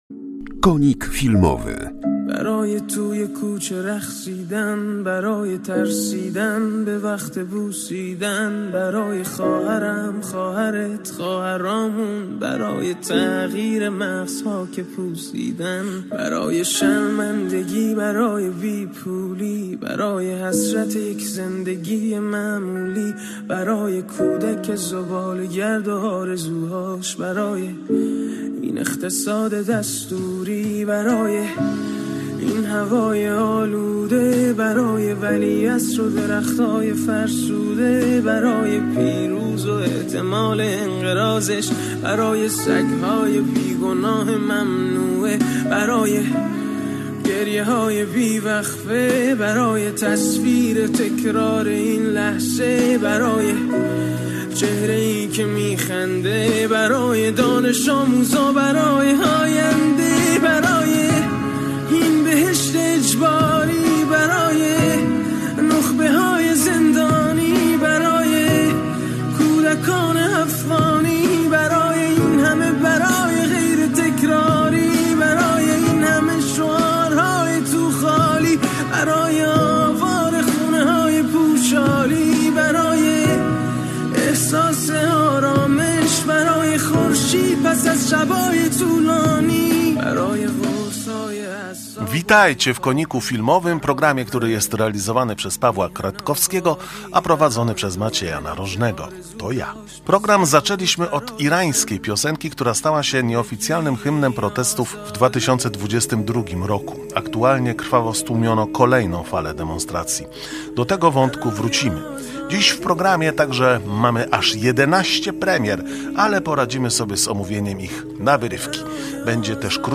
Program otwiera irańska piosenka, która stała się nieoficjalnym hymnem protestów w 2022 r. Będziemy mówić o premierze głośnego filmu z tego kraju "To był zwykły przypadek".